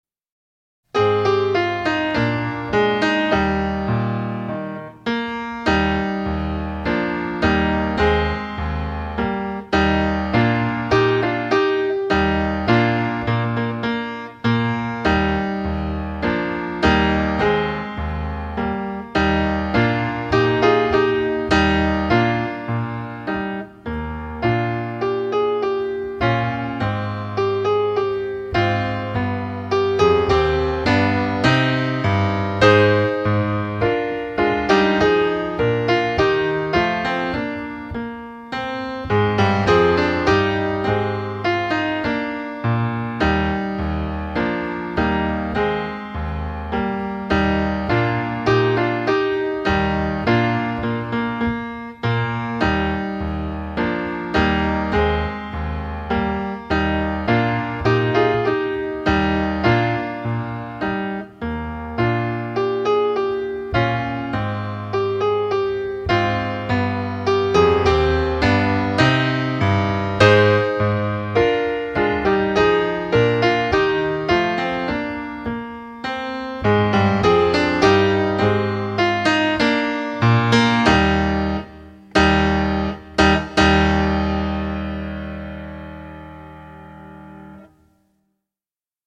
TROMBA SOLO • ACCOMPAGNAMENTO PIANO + BASE MP3
Trombone